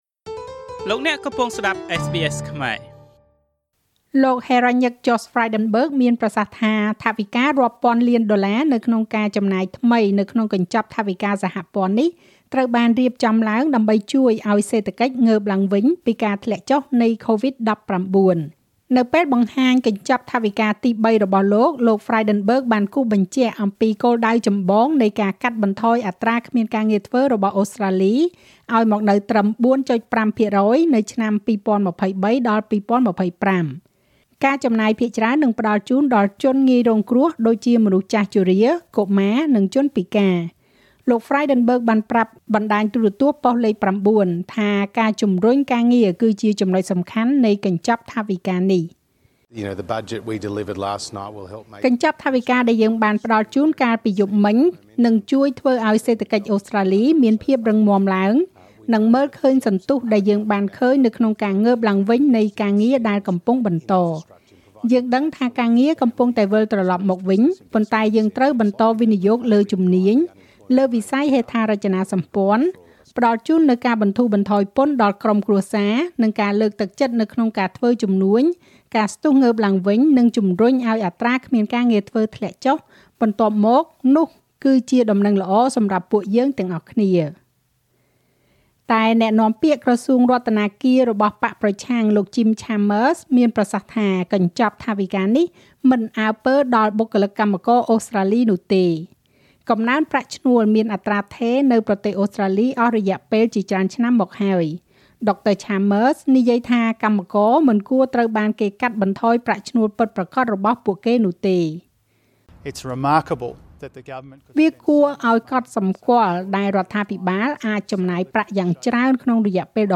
នាទីព័ត៌មានរបស់SBSខ្មែរ សម្រាប់ថ្ងៃពុធ ទី១២ ខែឧសភា ឆ្នាំ២០២១